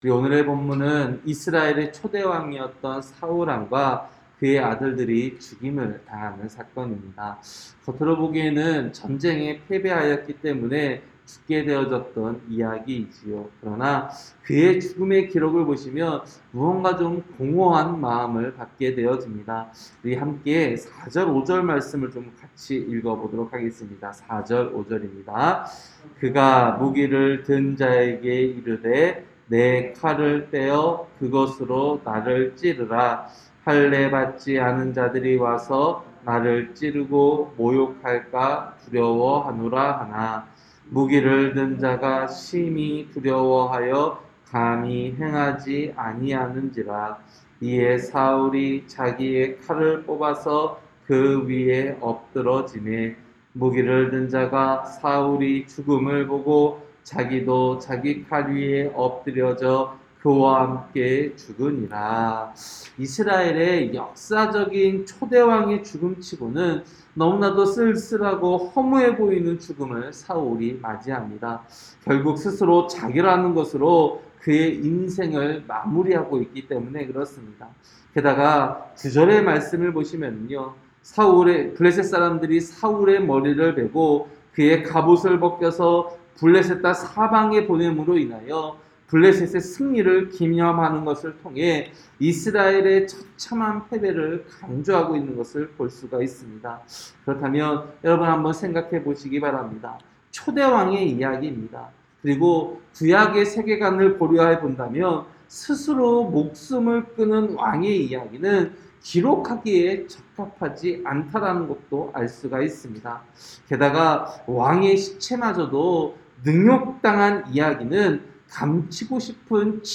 새벽설교-사무엘상 31장